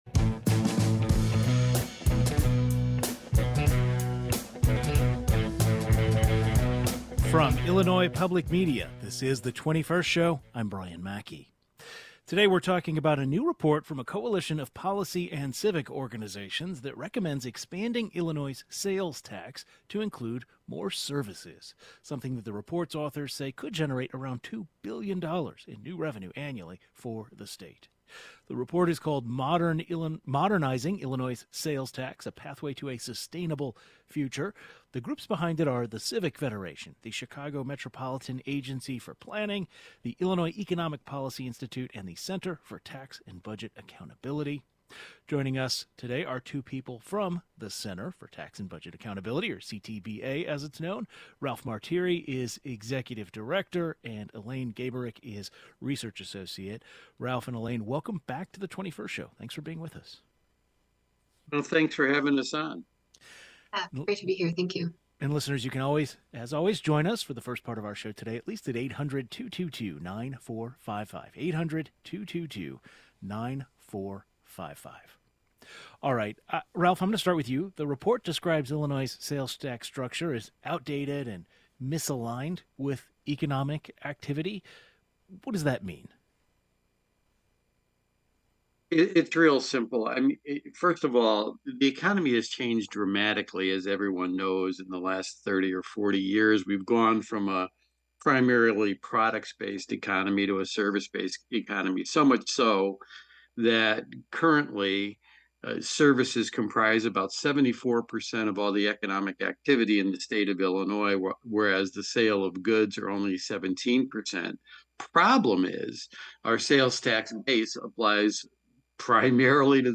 A new report from a coalition of policy and civic organizations recommends expanding Illinois' sales tax to include consumer services, something that the report's authors say could generate around $2 billion in new revenue annually for the state. Two of the authors discuss their reasoning behind these recommendations.